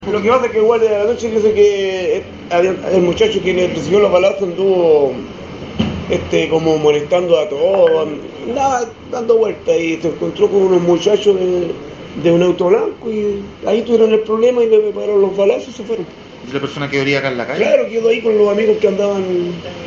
Un testigo señaló que la persona balaeada habría molestado a varias personas antes del ataque.